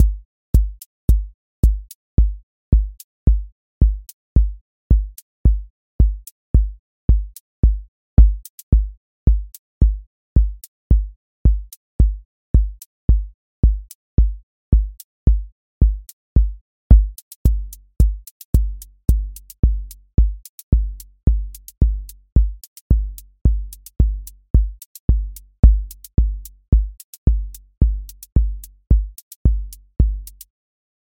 Four Floor Drive QA Listening Test house Template: four_on_floor April 18, 2026 ← Back to all listening tests Audio Four Floor Drive Open MP3 directly Selected Components macro_house_four_on_floor voice_kick_808 voice_hat_rimshot voice_sub_pulse Test Notes What This Test Is Four Floor Drive Selected Components macro_house_four_on_floor voice_kick_808 voice_hat_rimshot voice_sub_pulse